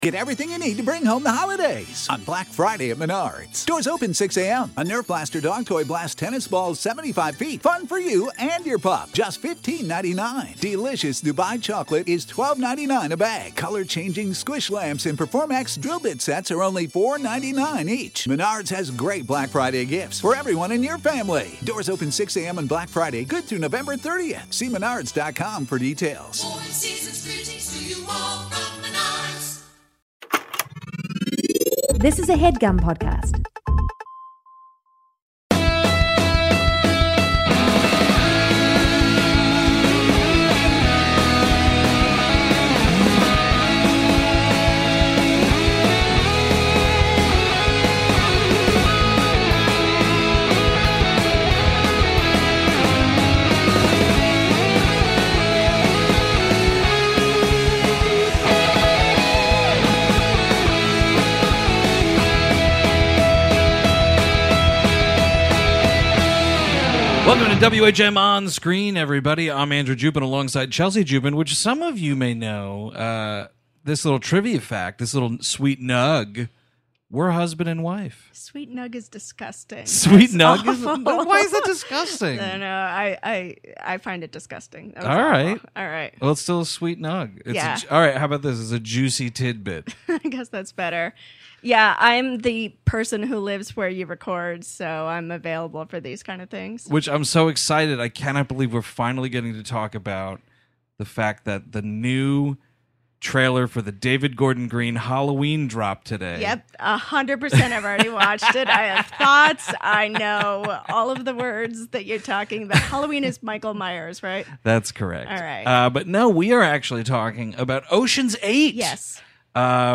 chat one-on-one